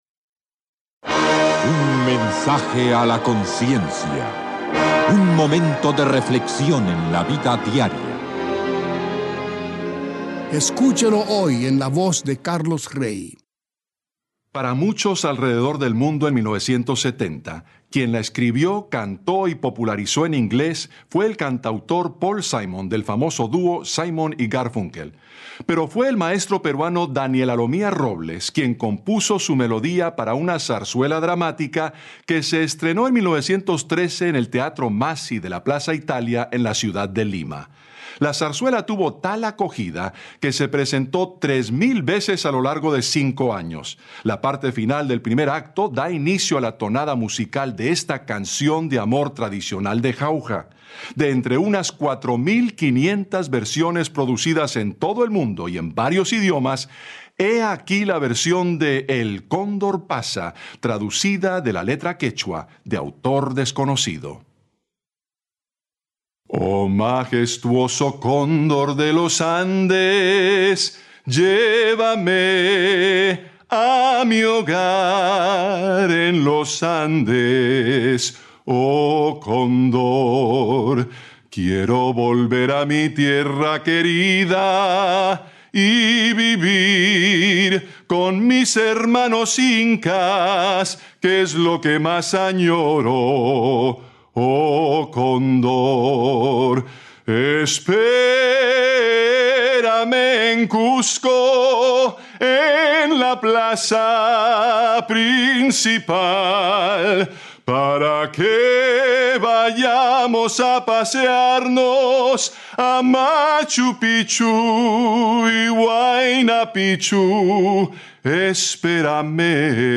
Canción cantada